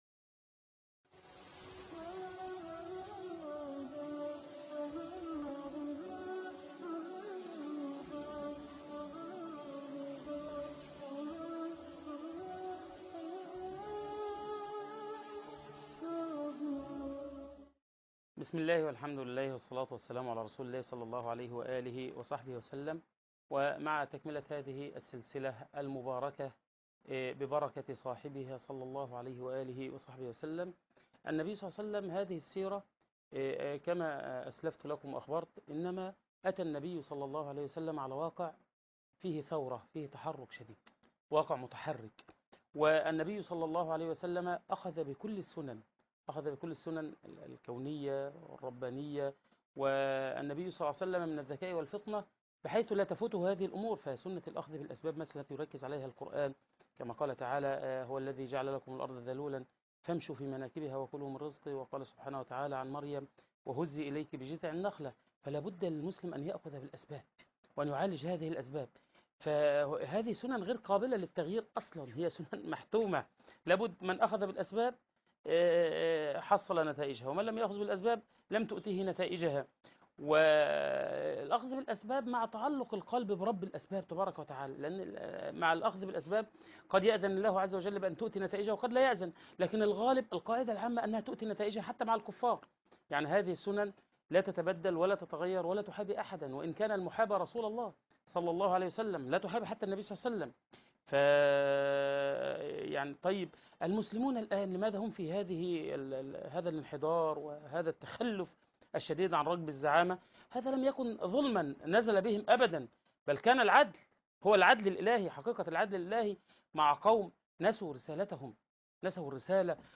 السيرة النبوية {المحاضرة الثانية} صورة عامة عن العالم قبل بعثة النبي صلى الله عليه وسلم